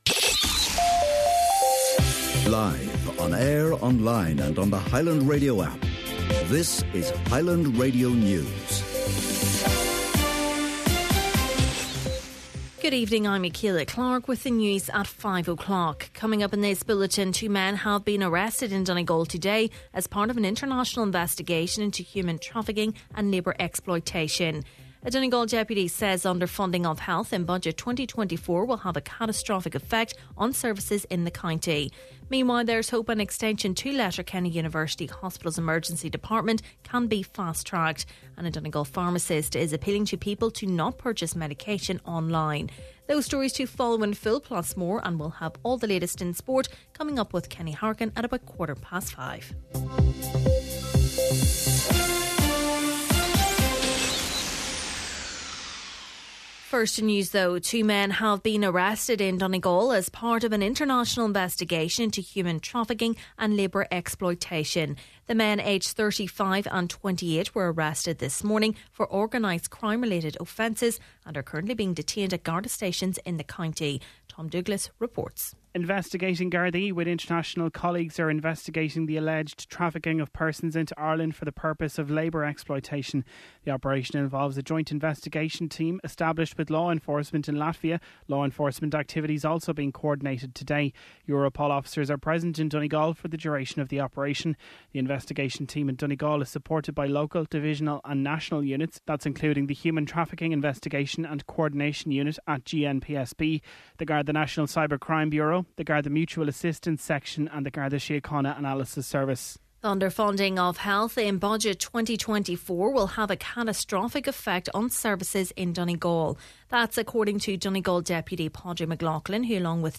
Main Evening News, Sport and Obituaries – Tuesday October 24th